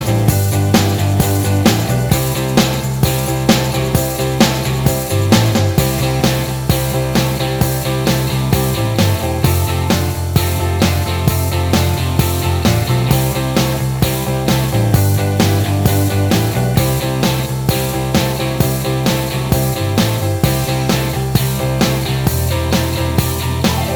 Minus Lead Guitar Rock 3:39 Buy £1.50